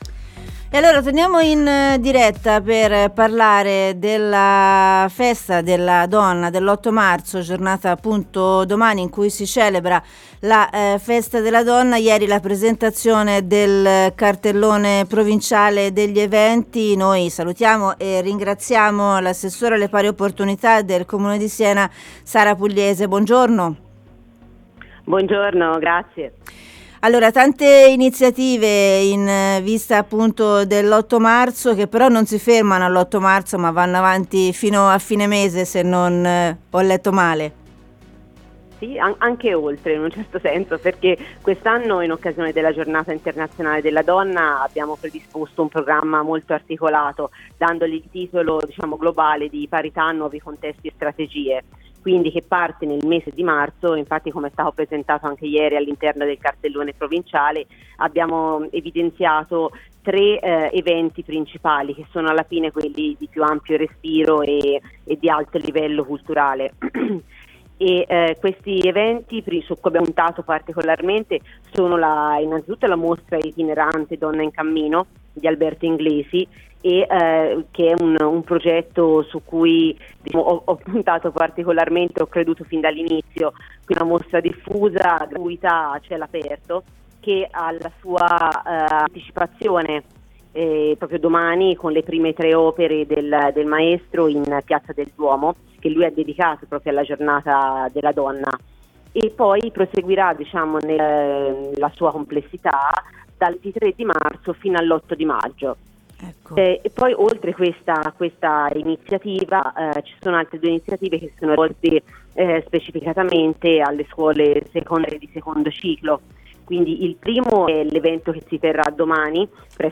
Interviste
Sara Pugliese (Assessore Pari Opportunità comune di Siena)